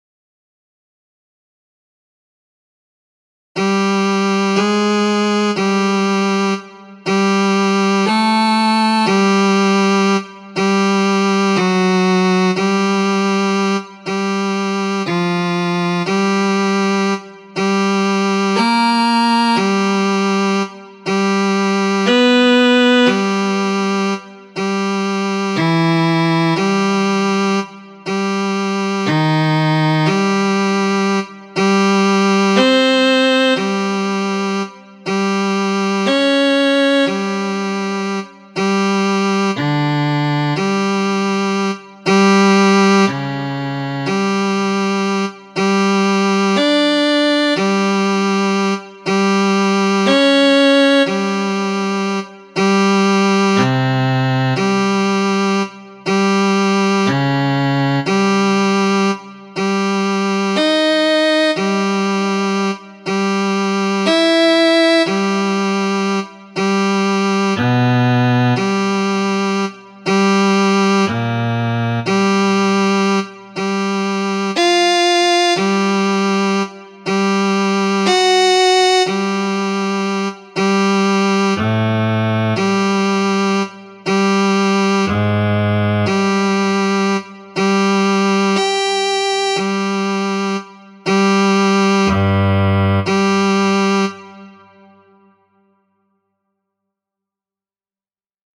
The following eartraining routine is quite difficult to sing. It contains all of the intervals, ascending and descending, within one octave. The tone center is G and the overall range is two octaves.
Just for a little variety the exercise is written in 7/4.
Each note is a half note with a chance for a quick breath at the end of each measure (tempo of the file is quarter note = 120, but on the internet tempo is kind of 'iffy').